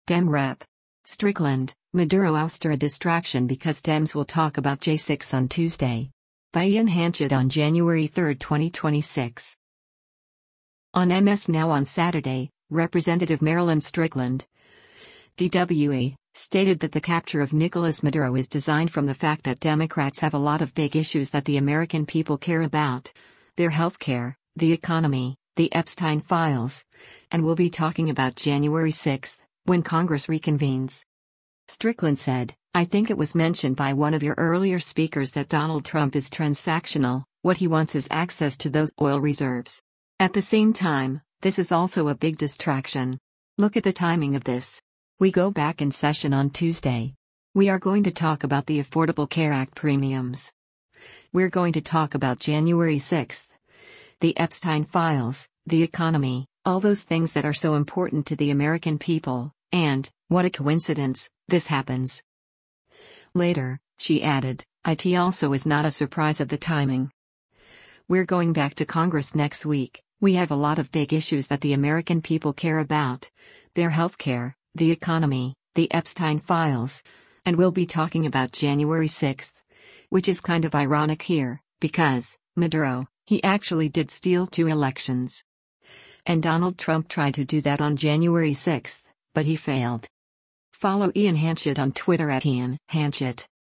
On MS NOW on Saturday, Rep. Marilyn Strickland (D-WA) stated that the capture of Nicolas Maduro is designed from the fact that Democrats “have a lot of big issues that the American people care about, their healthcare, the economy, the Epstein files, and we’ll be talking about January 6,” when Congress reconvenes.